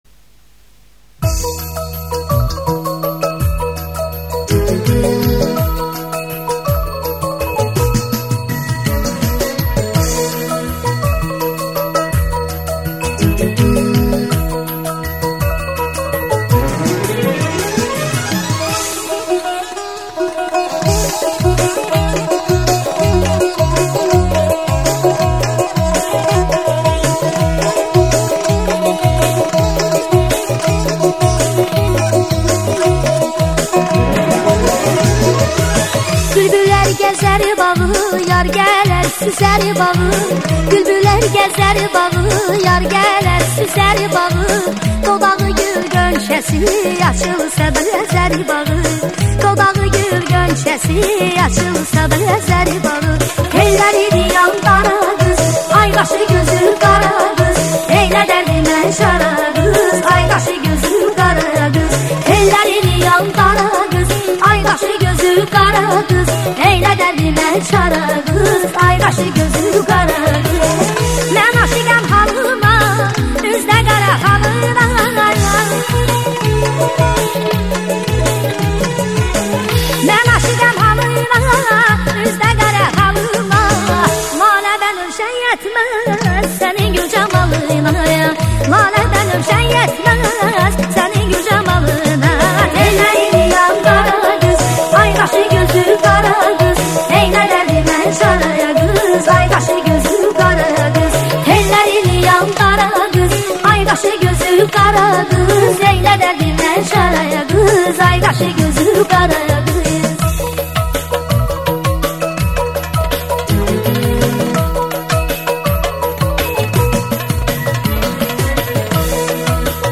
اهنگ اذری